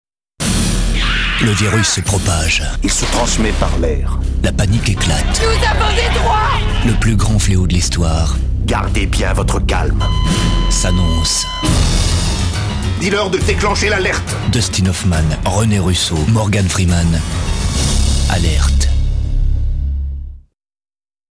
Pubs Radio: